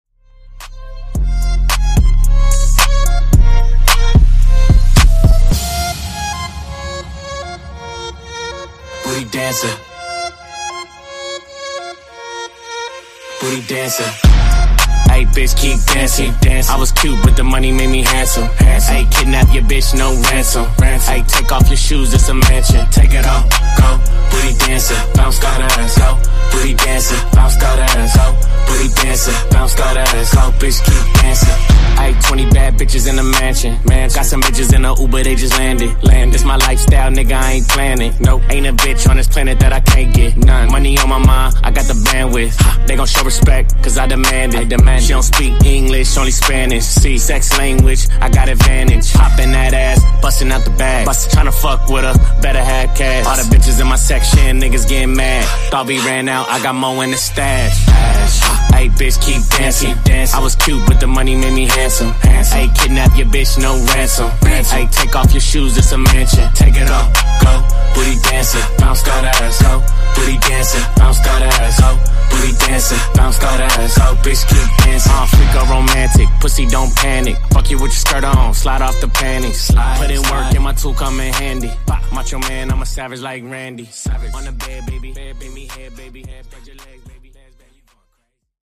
Genre: RE-DRUM
Clean BPM: 121 Time